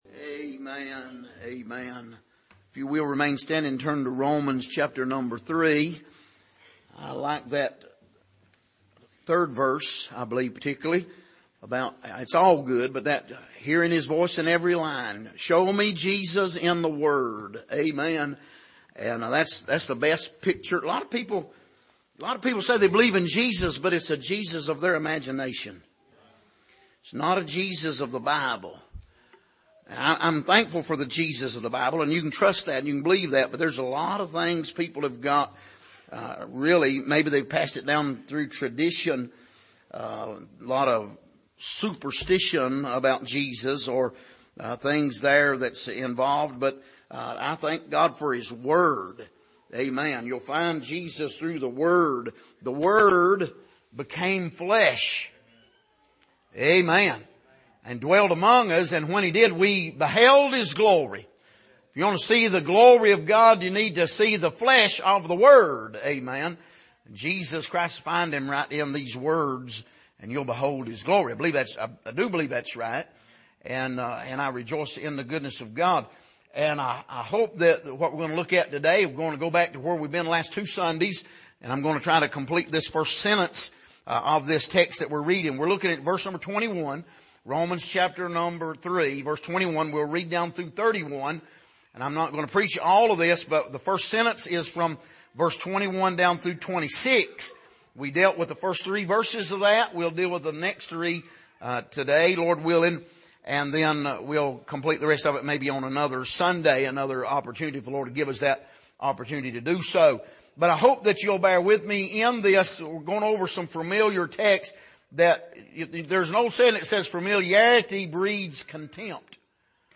Passage: Romans 3:21-31 Service: Sunday Morning